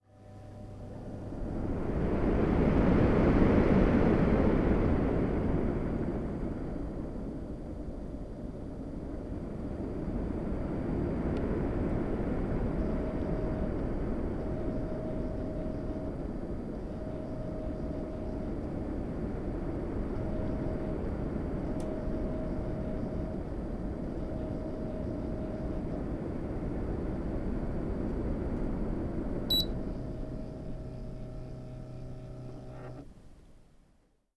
Ruido del aire acondicionado
aire acondicionado
ruido
Sonidos: Industria